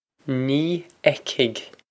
Nee ec-hig
This is an approximate phonetic pronunciation of the phrase.